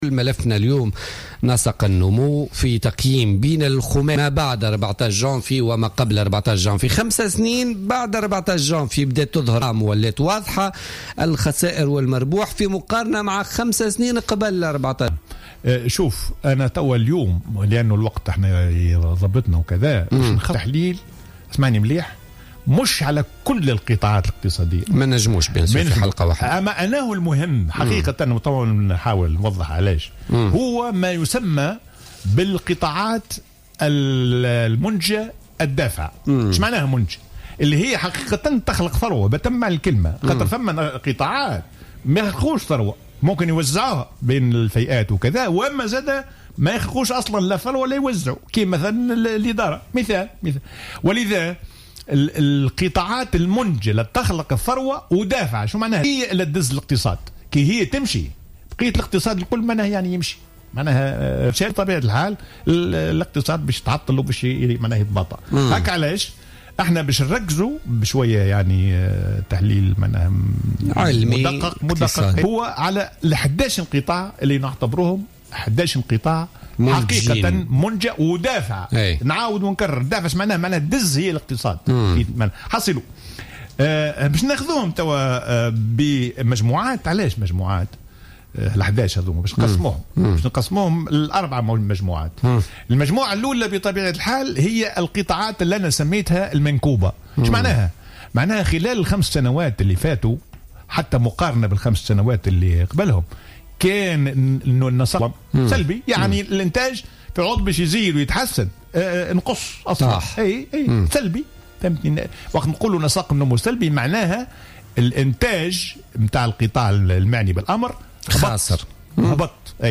قال وزير المالية السابق والخبير الاقتصادي حسين الديماسي في تصريح للجوهرة أف أم في برنامج بوليتكا لليوم الجمعة 04 مارس 2016 إن قطاع الفلاحة والصيد البحري هو القطاع الوحيد الذي ازدهر بعد الثورة وتحسن نسق نموه من بين 11 قطاع منتج للثروة ودافع للاقتصاد في البلاد.